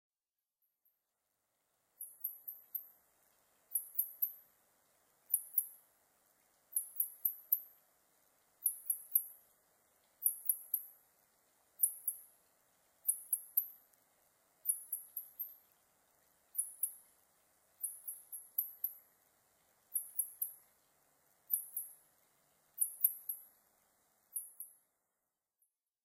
コウモリ sp.　a kind of a Bat
Mic.: Sound Professionals SP-TFB-2  Binaural Souce